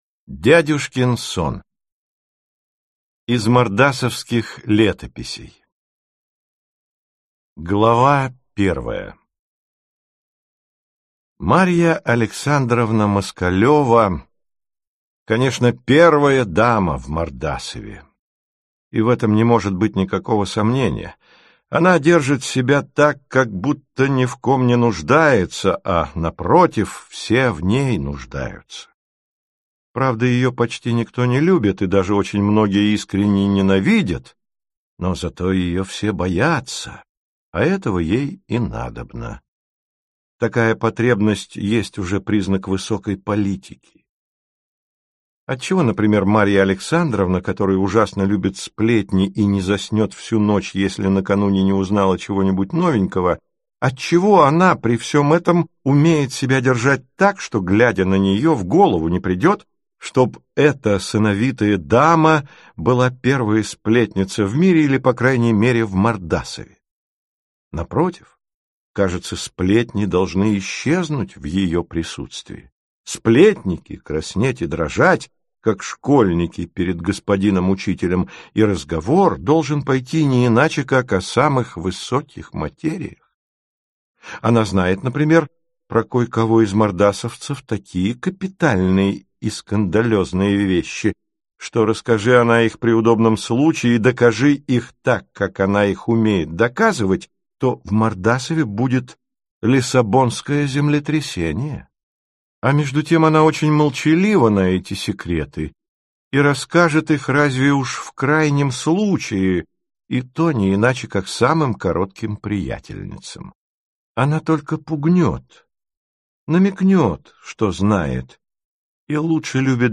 Аудиокнига Дядюшкин сон | Библиотека аудиокниг